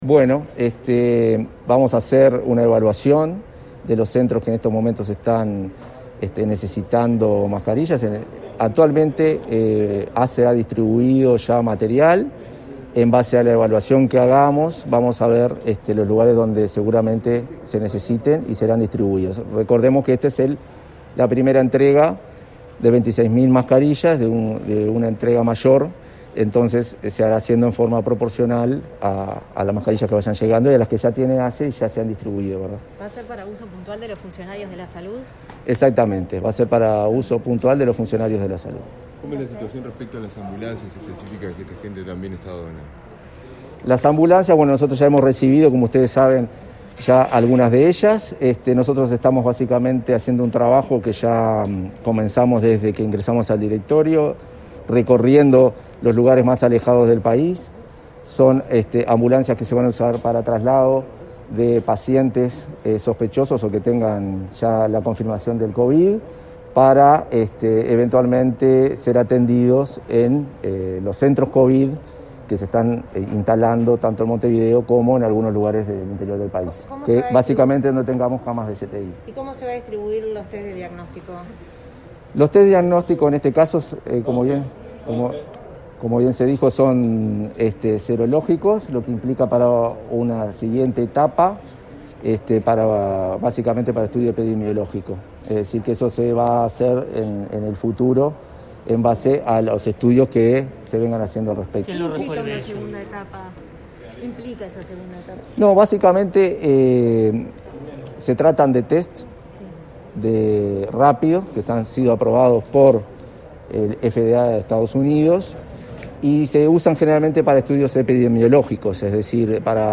El vicepresidente de ASSE, Marcelo Sosa, informó a la prensa sobre la distribución del material sanitario donado por varias empresas que integran el grupo Nos Cuidamos entre Todos entre el personal de la salud. Habló del trabajo en el Hospital Español, centro de referencia COVID-19, de la situación en el Hospital Vilardebó y de los testeos en asentamientos.